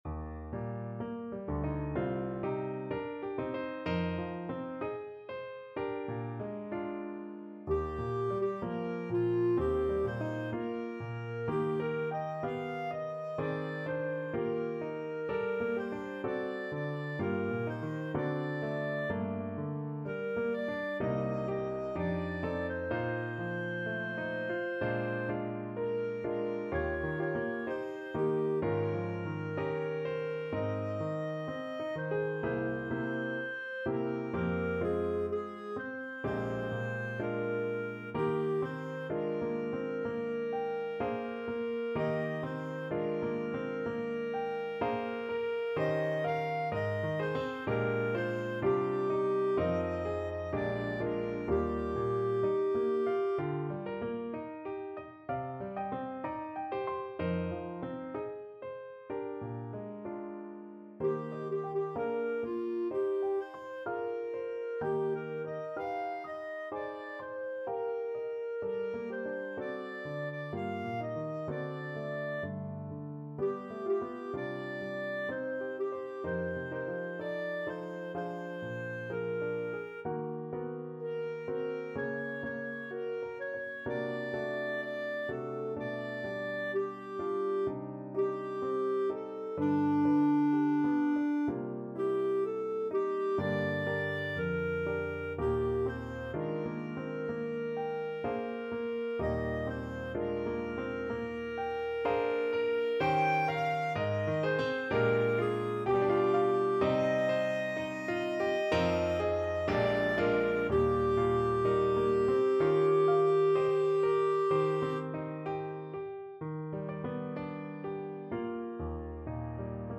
4/4 (View more 4/4 Music)
Classical (View more Classical Clarinet Music)